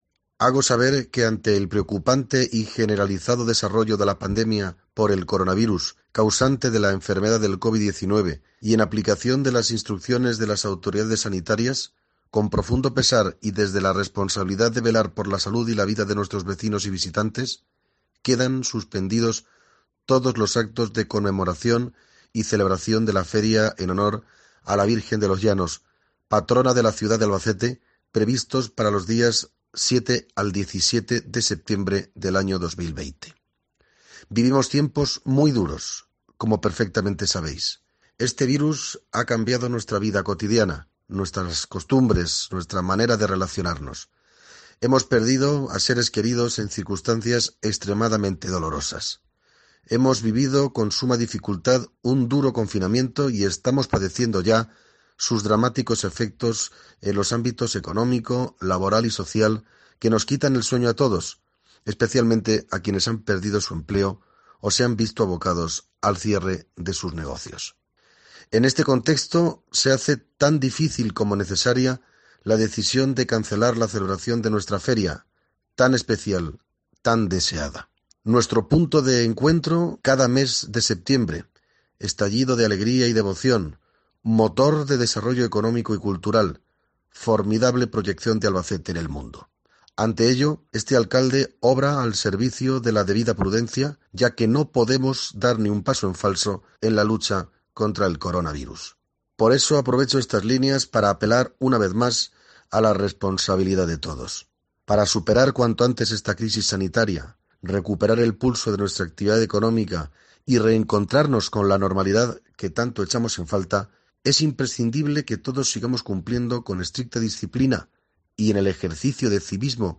Audio bando del alcalde de Albacete, Vicente Casañ